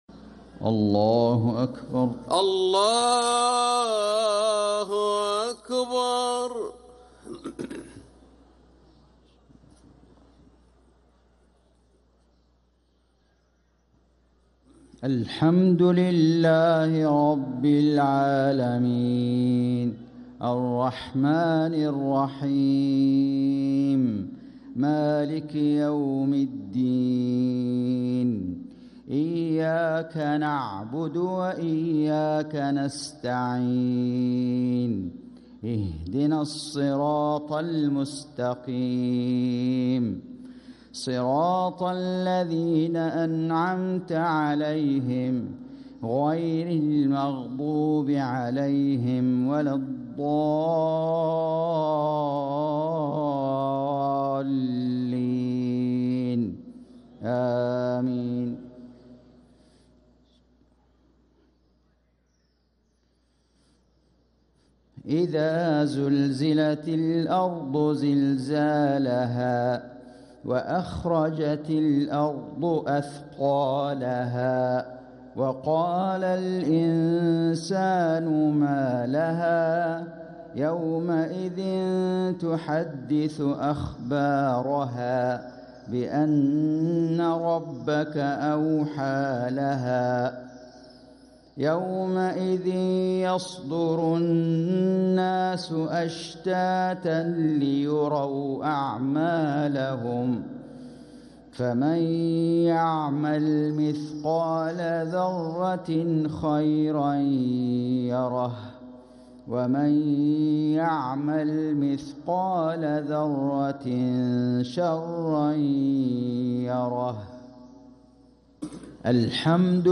صلاة المغرب ٧-٧-١٤٤٦هـ | سورة الزلزلة و القارعة كاملة | Maghrib prayer from Surah Az-Zalzala & al-Qari`ah |7-1-2025 > 1446 🕋 > الفروض - تلاوات الحرمين